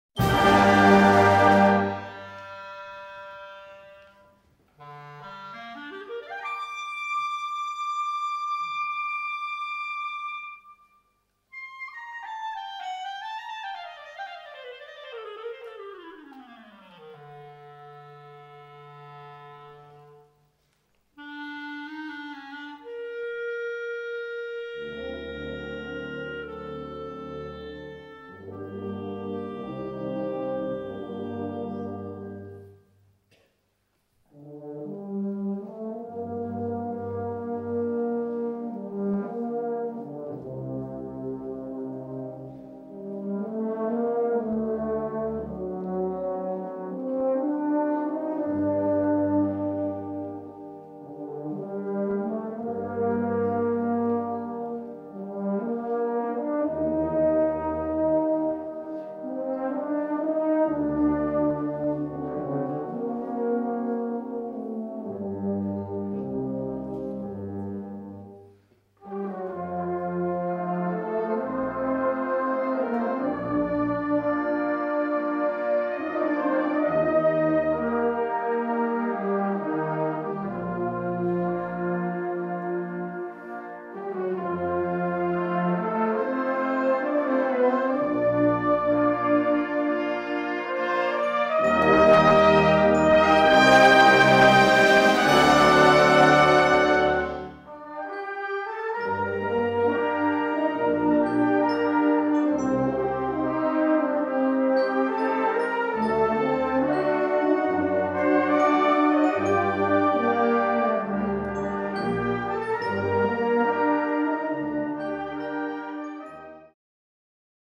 Gattung: Czardas
Besetzung: Blasorchester